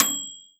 bell.wav